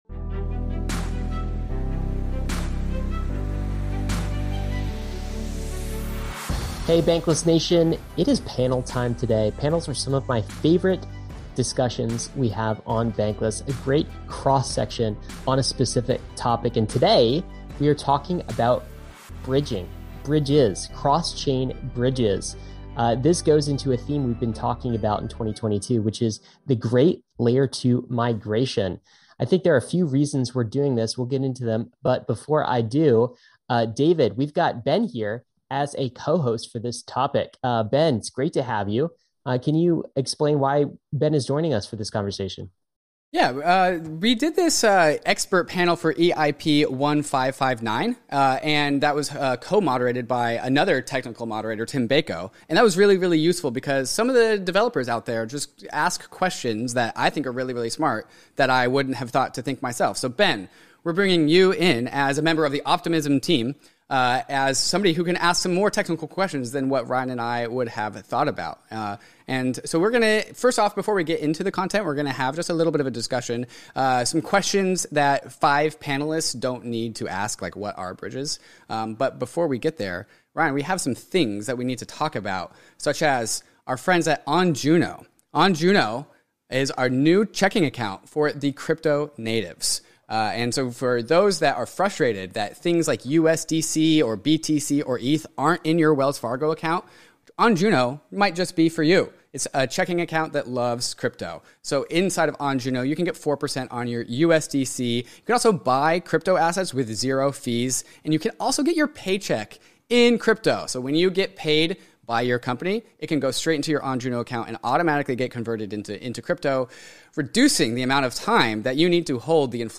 The Great L2 Migration | Cross-Chain L2 Panel